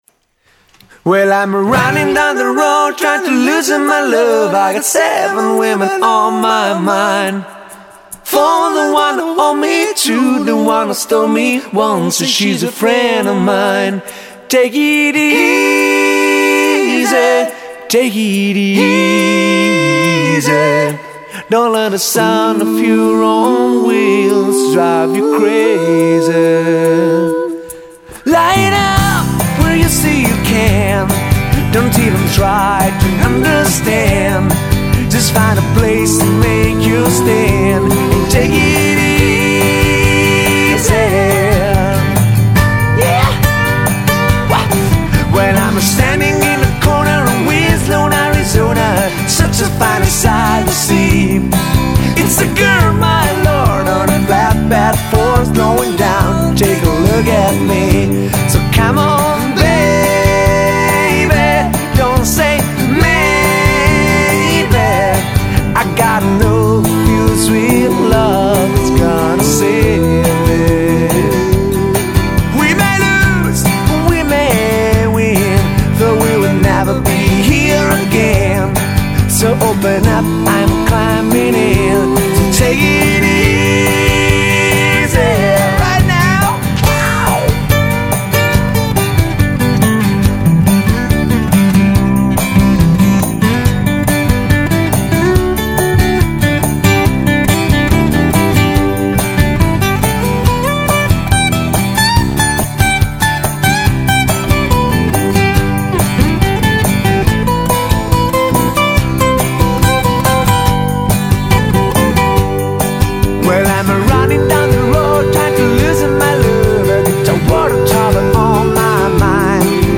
cover di vario genere riarrangiate in chiave acustica